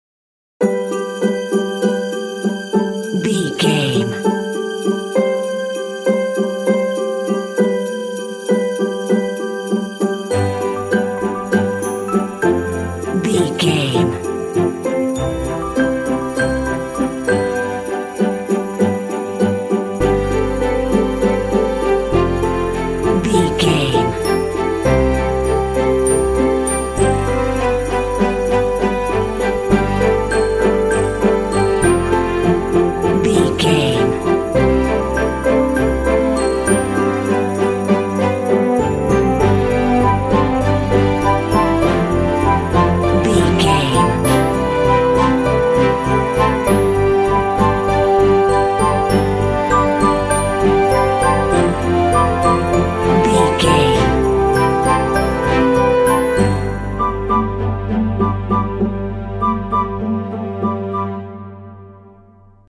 Uplifting
Ionian/Major
festive
cheerful/happy
joyful
light
strings
percussion
piano
cinematic